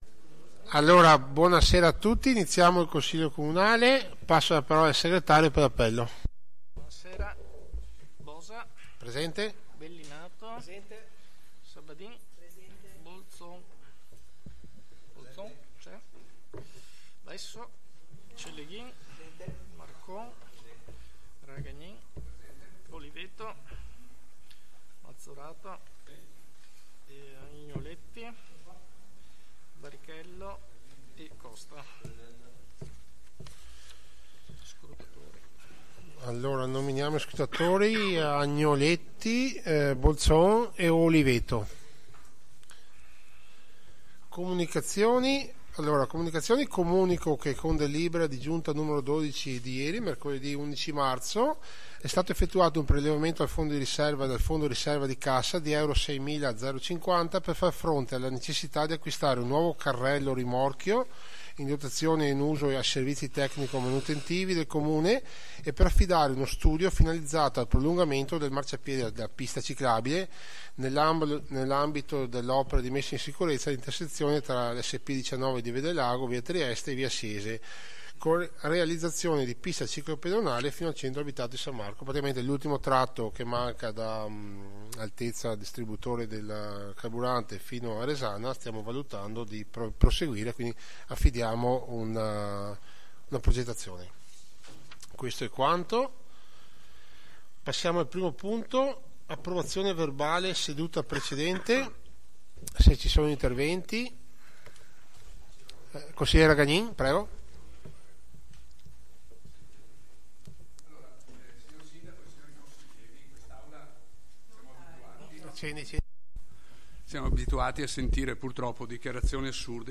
Seduta di Consiglio Comunale del 12/03/2026